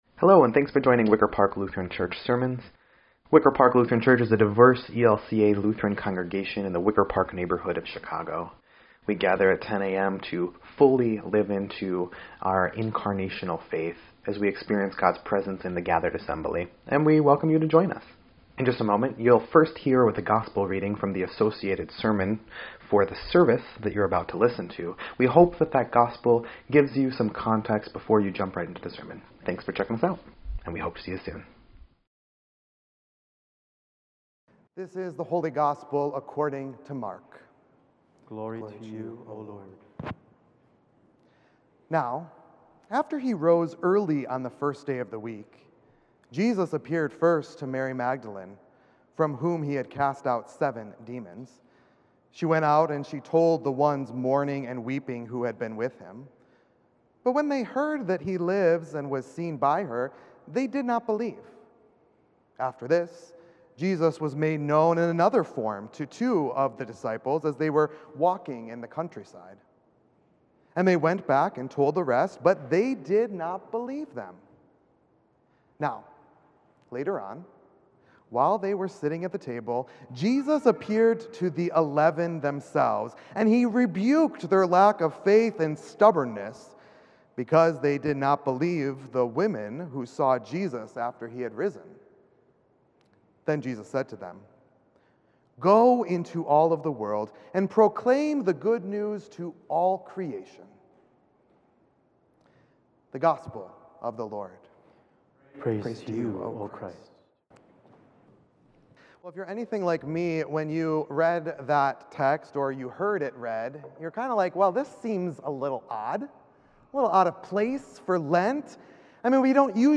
3.6.22-Sermon_EDIT.mp3